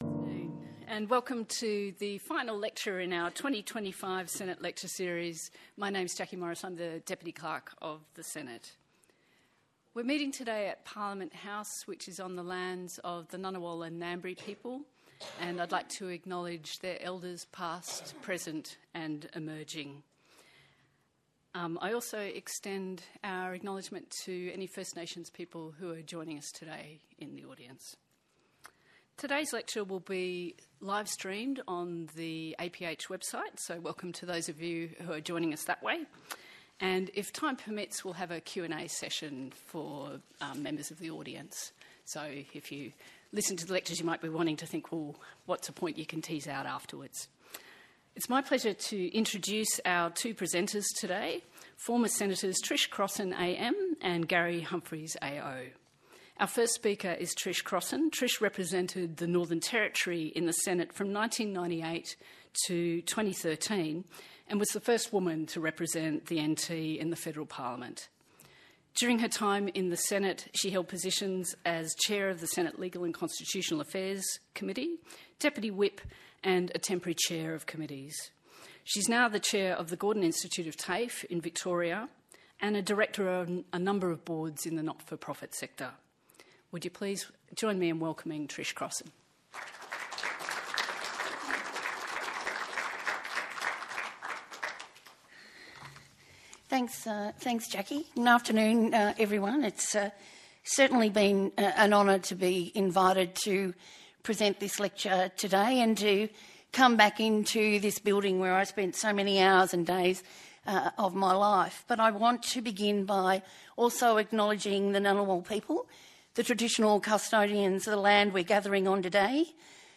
Listen to a recoding of the lecture: Annual Harry Evans lecture: Reflections on Senate committees